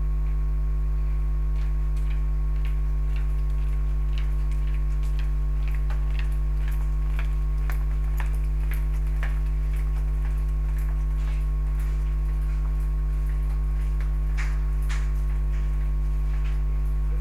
It consists of 43 audio-visual events captured via two cameras (Canon VC-C50i) and two USB microphones in a corridor scenario.
A person walks toward Cam2
Mic 1 audio